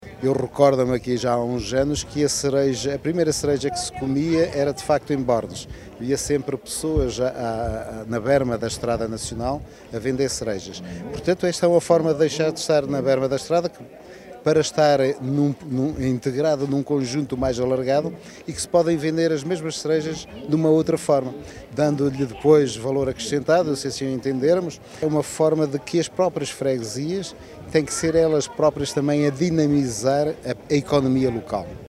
Tirar os vendedores da berma da estrada e colocá-los num local com maior oportunidade de negócio foi um dos objetivos para o nascimento desta feira, numa aldeia desde sempre ligada à produção de cereja, confessa Duarte Moreno, presidente da Câmara Municipal de Macedo de Cavaleiros.